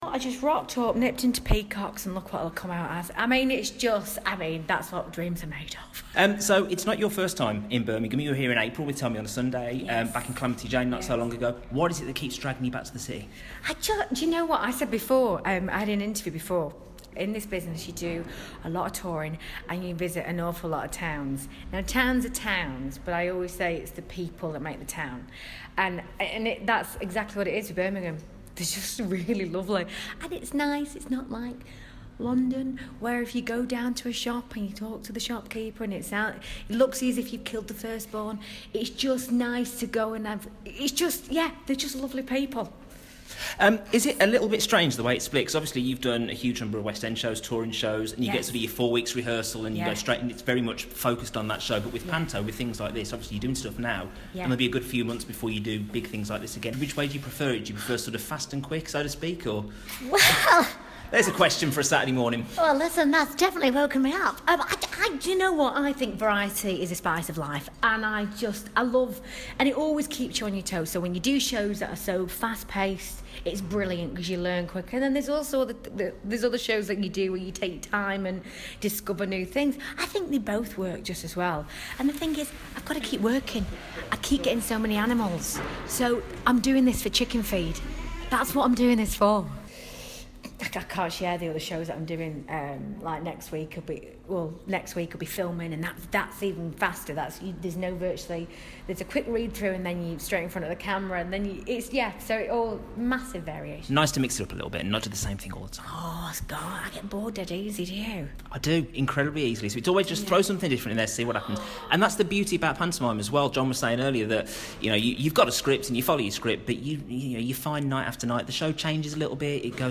Jodie Prenger - first discovered in the BBC 1 show 'I'd Do Anything' in 2008 - has had a string of West End roles to her name, in Oliver, Spamalot, Annie and One Man Two Guv'nors. She's taking to the Birmingham Hippodrome stage for their 2016 pantomime and I caught up with her at the launch.